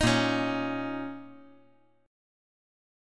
A#mbb5 Chord
Listen to A#mbb5 strummed